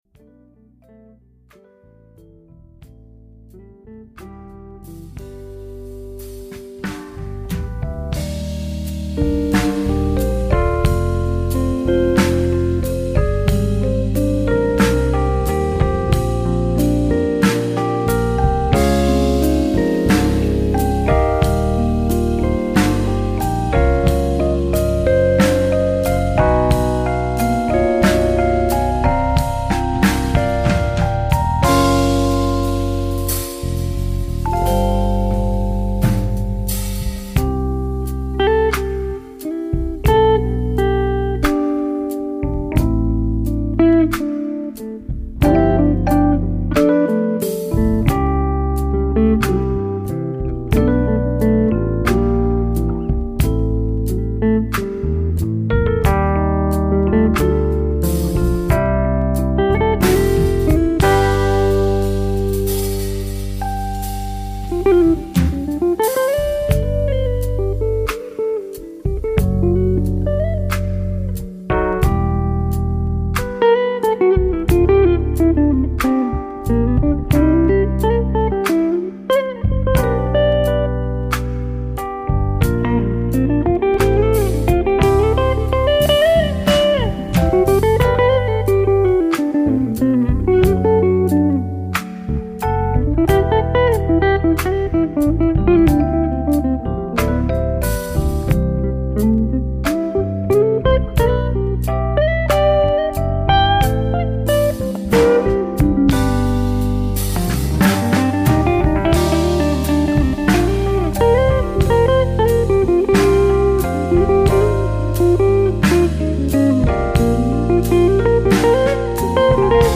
Electric and Acoustic Guitars
Keyboards
Electric and Acoustic Bass
Drums